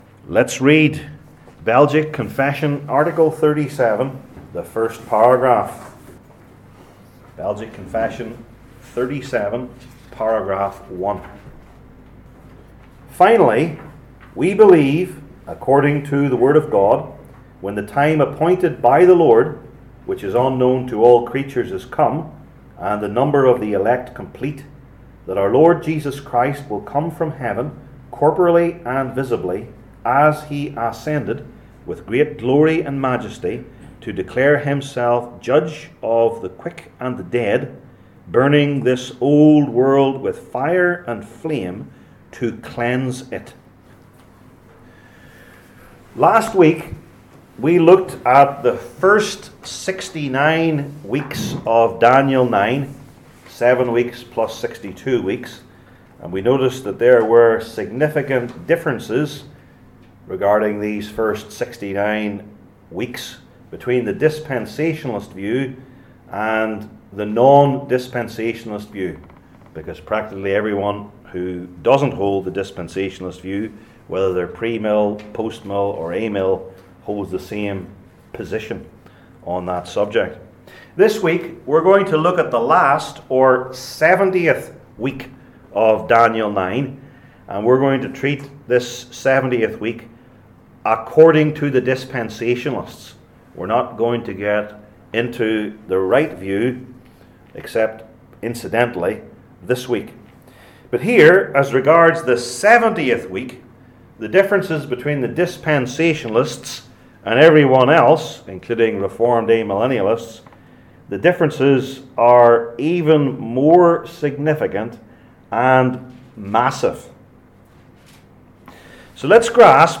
Passage: Daniel 9:20-27 Service Type: Belgic Confession Classes